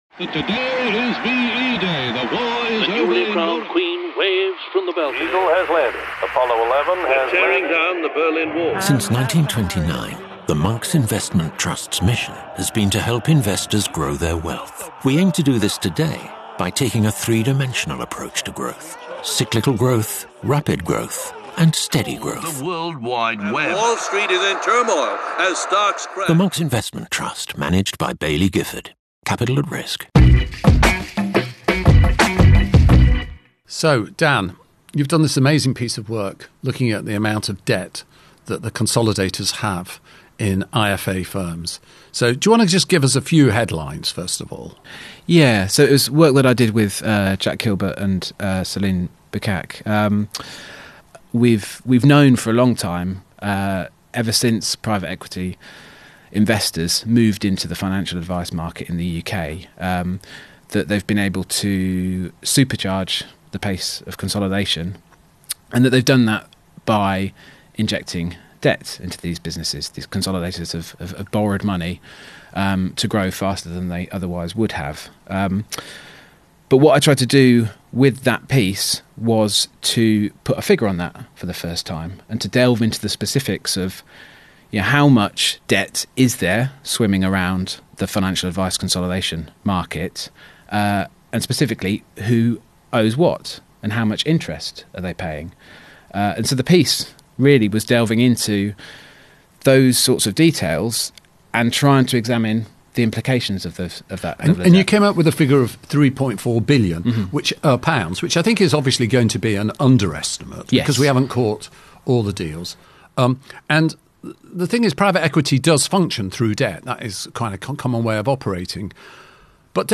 Exclusive interviews and lively chat with a diverse mix of figures from the financial planning profession, personal finance and financial services.